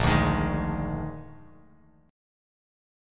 Bb11 chord